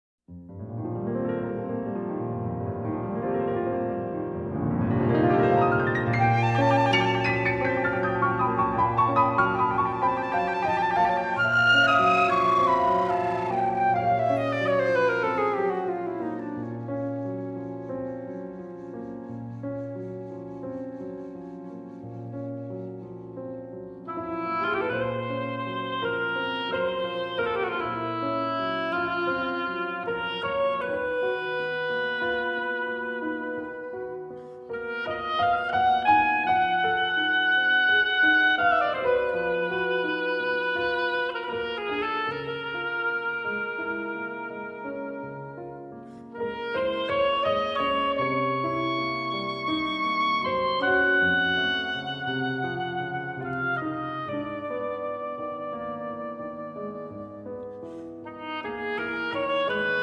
for flute, oboe and piano